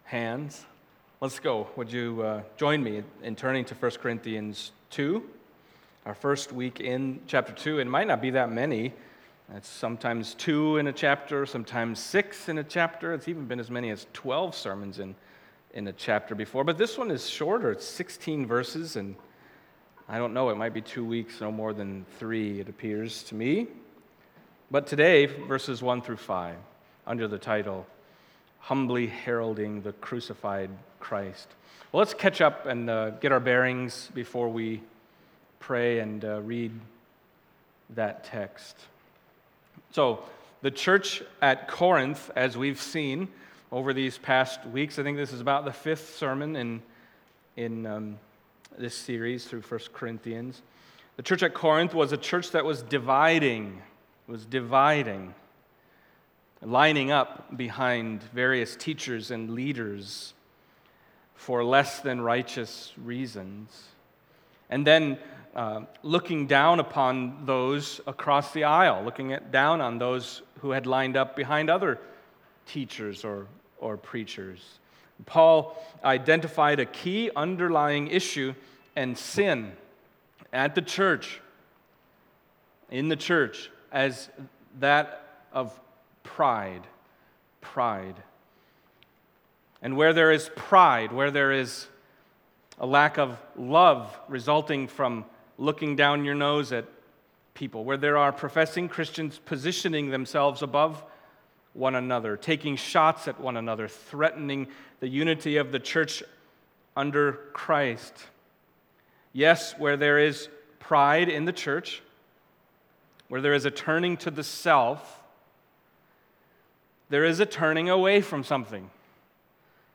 Passage: 1 Corinthians 2:1-5 Service Type: Sunday Morning